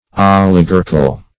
Oligarchal \Ol`i*gar"chal\, a.